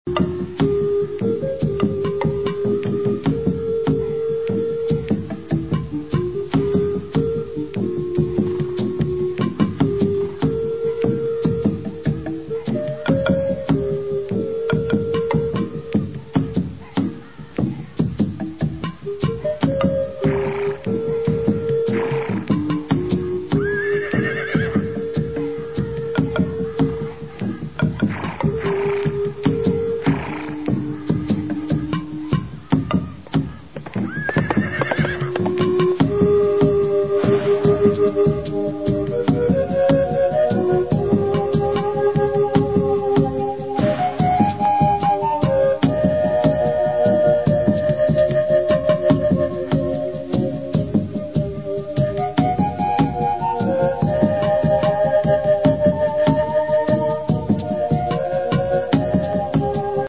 Этно-трансовая музыка.